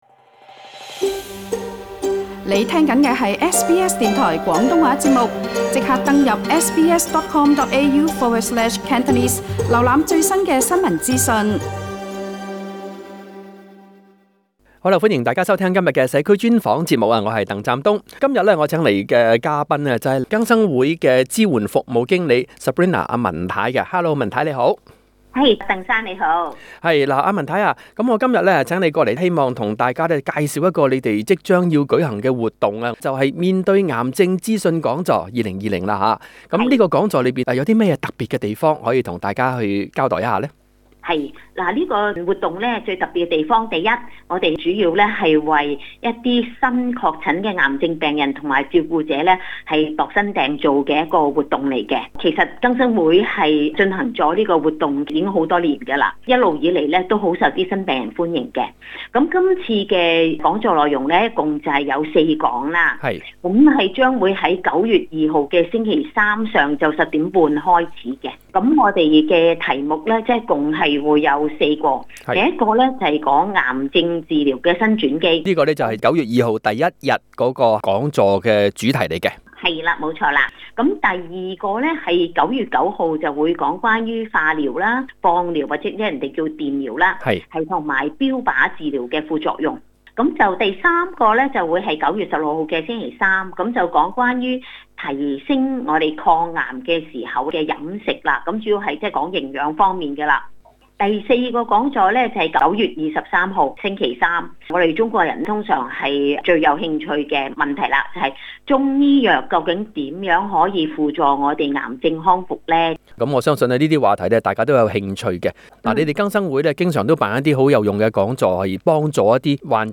訪問